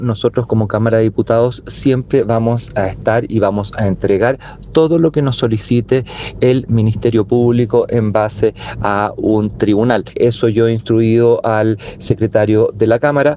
Ante estos antecedentes, el presidente de la Cámara de Diputadas y Diputados, José Miguel Castro, lamentó las nuevas investigaciones que involucran a parlamentarios, y aseguró que la Corporación prestará total colaboración con la justicia para esclarecer los hechos.